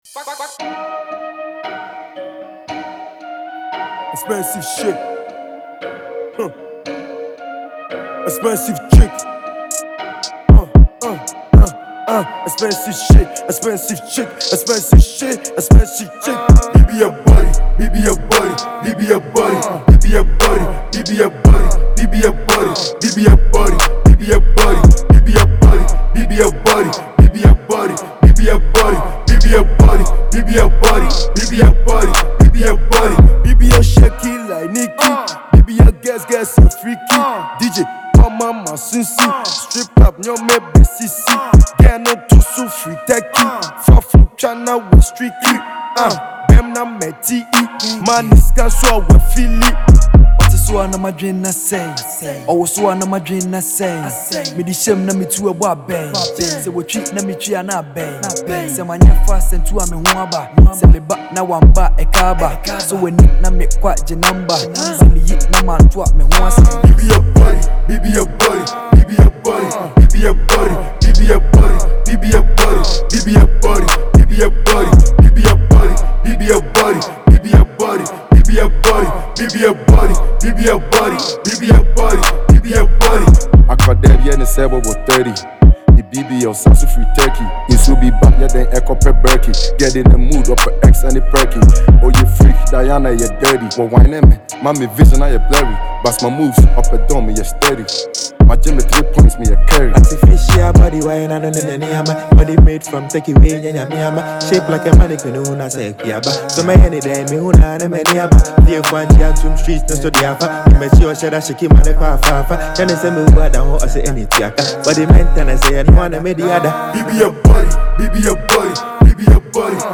Ghana MusicMusic
Ghanaian drill sensation
tough Asakaa anthem